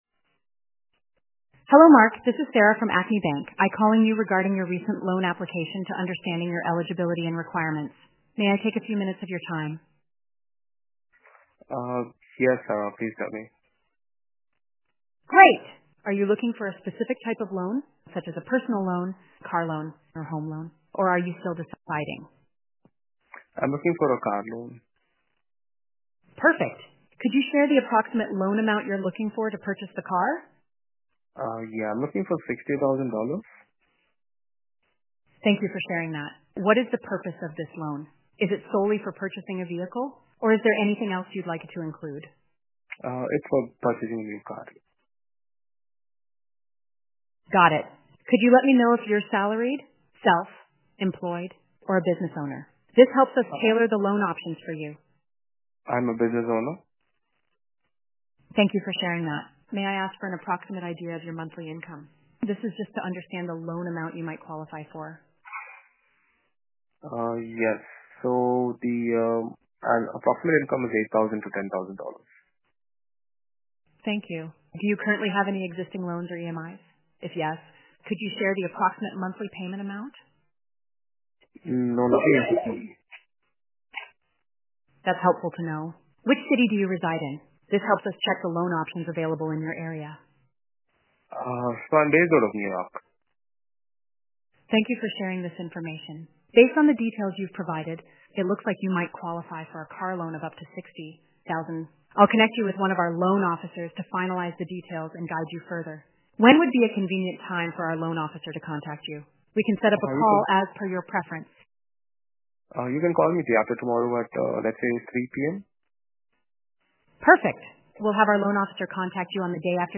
Experience how our AI Voice Bot seamlessly handles real-world financial service interactions.
• Automating Loan Application Inquiries with AI Voice Assistance